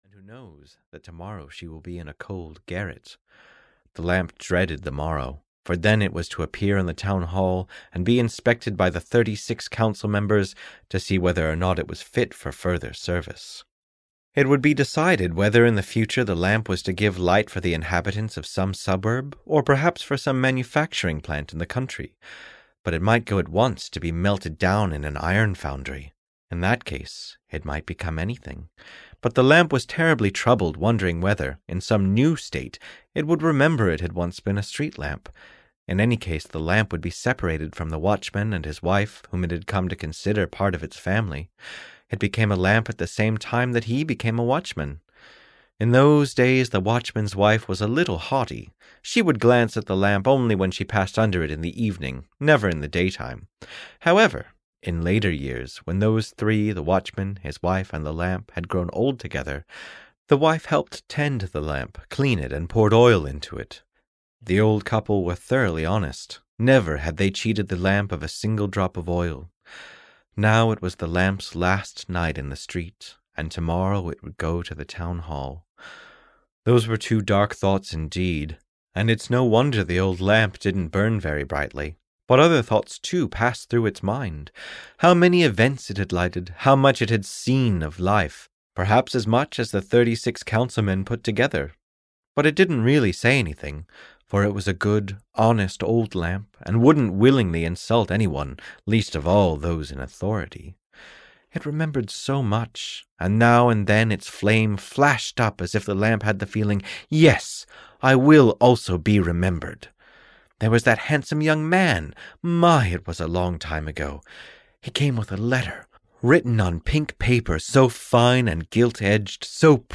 The Old Street Lamp (EN) audiokniha
Ukázka z knihy